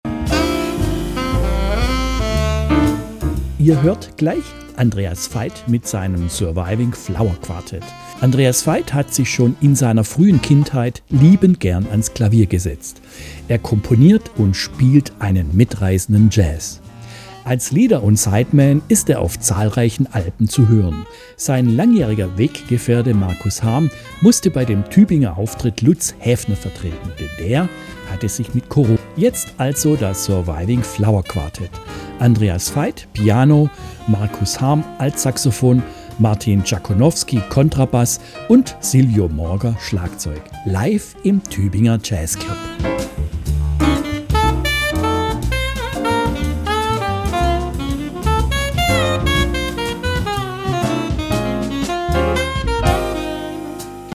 groovenden Modern Jazz
Piano
Altsaxofon
Kontrabass
Schlagzeug